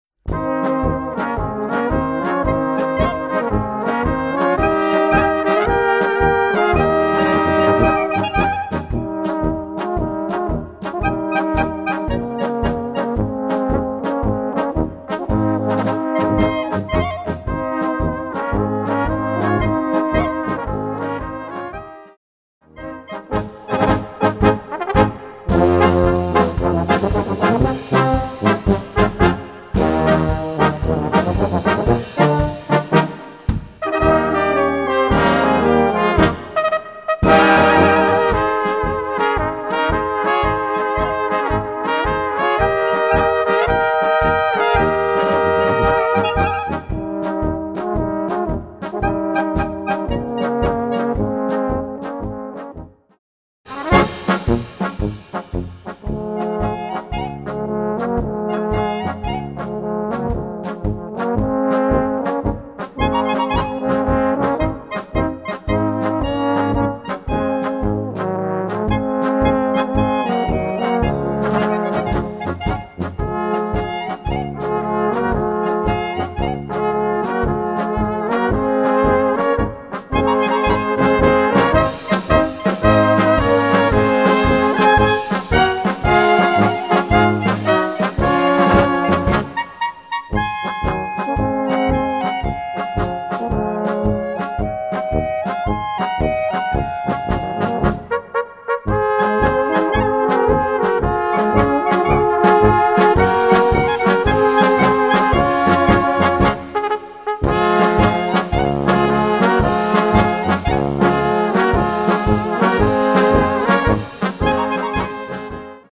Gattung: Polka
Besetzung: Blasorchester
Beschwingt und einfach schön!